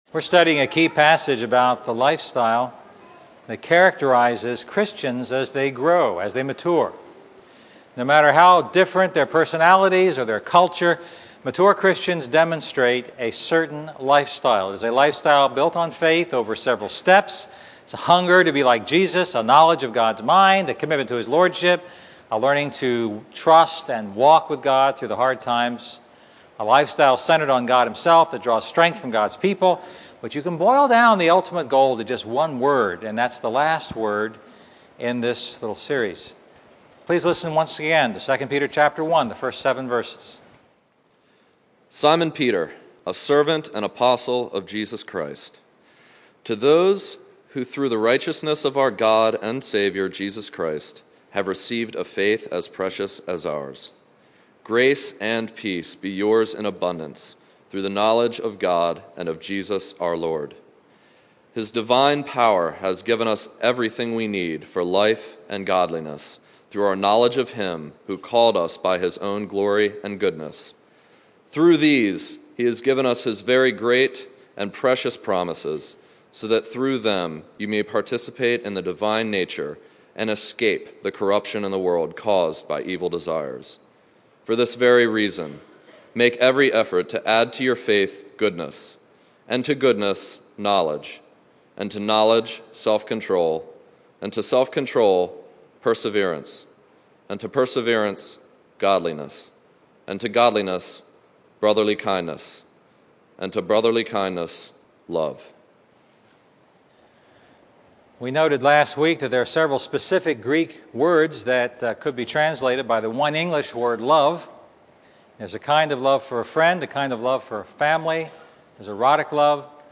A message from the series "The Christian Lifestyle."